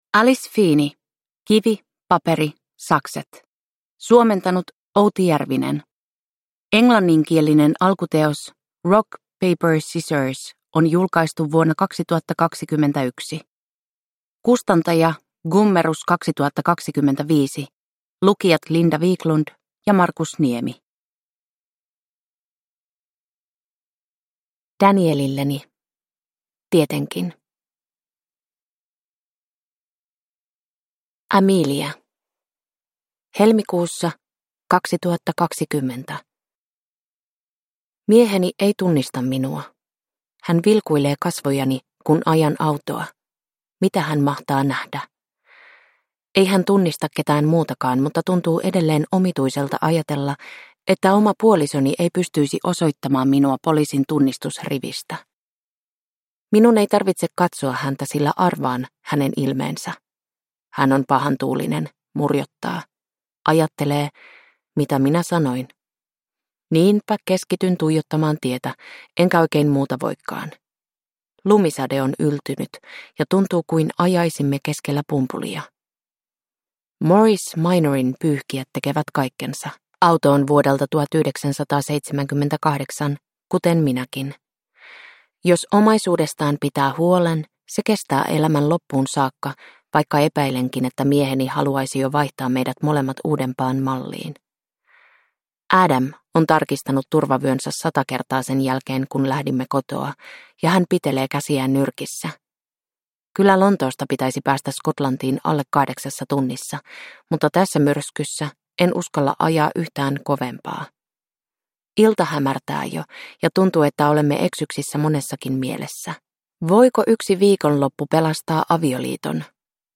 Kivi, paperi, sakset (ljudbok) av Alice Feeney